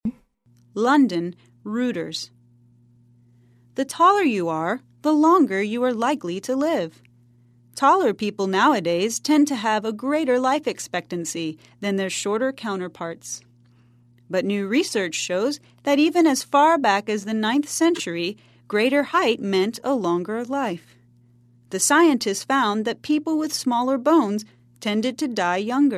在线英语听力室赖世雄英语新闻听力通 第69期:矮子不长寿的听力文件下载,本栏目网络全球各类趣味新闻，并为大家提供原声朗读与对应双语字幕，篇幅虽然精短，词汇量却足够丰富，是各层次英语学习者学习实用听力、口语的精品资源。